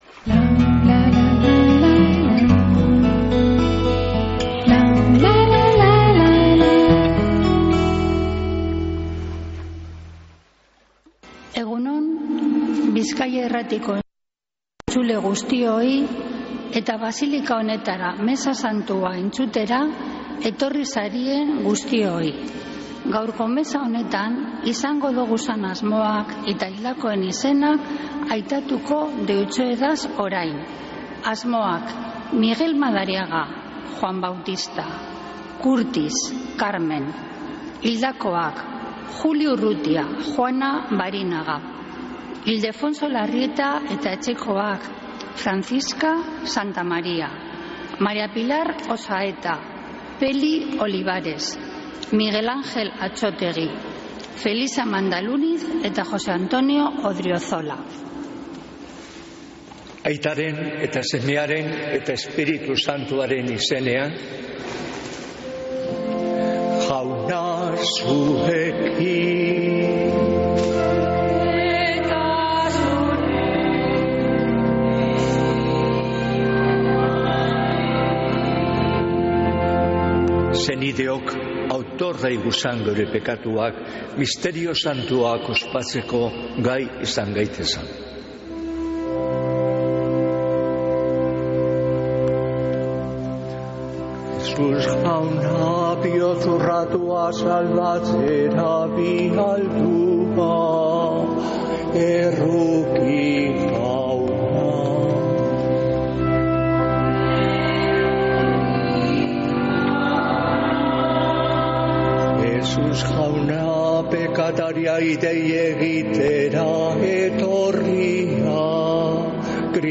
Mezea Begoñako basilikatik | Bizkaia Irratia
Mezea (25-07-21)